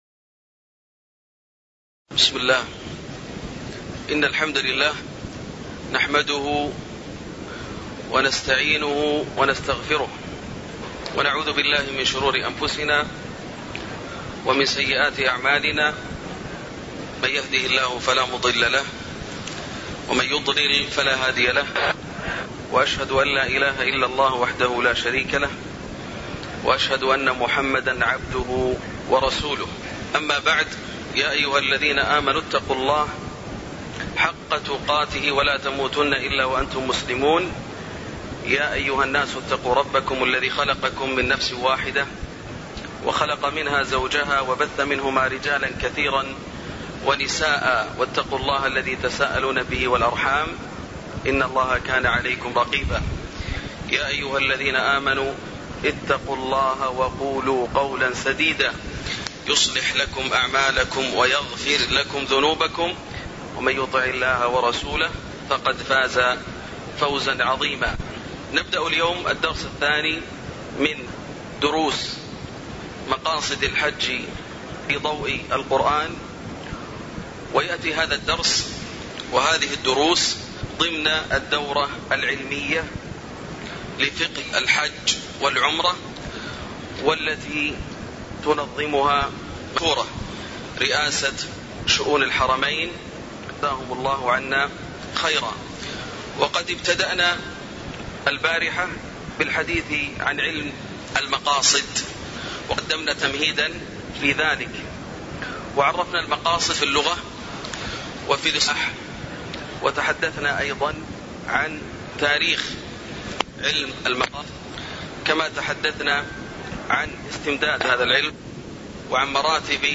تاريخ النشر ٢٣ ذو القعدة ١٤٣٩ هـ المكان: المسجد النبوي الشيخ: فضيلة الشيخ ياسر الدوسري فضيلة الشيخ ياسر الدوسري المقصد الاول هو مقصد توحيد الله سبحانه وتعالى (02)